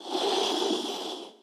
SFX_Door_Slide_03.wav